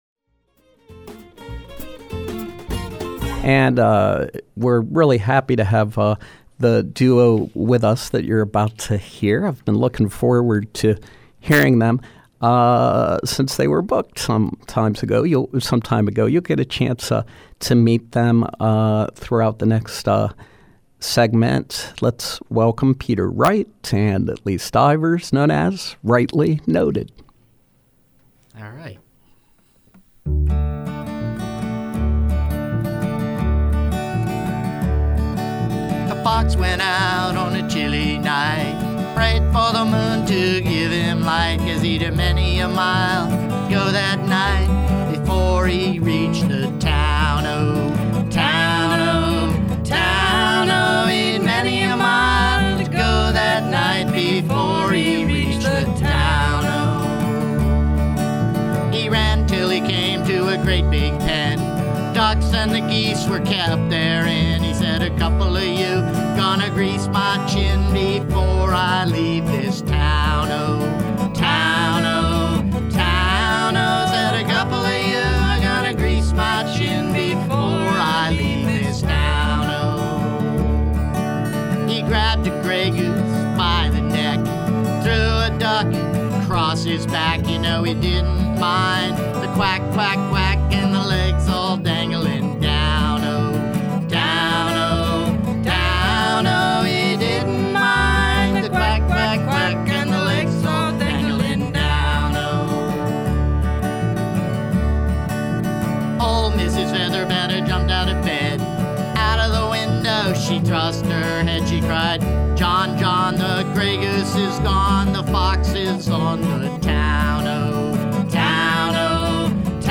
guitar, vocals
mandolin, upright bass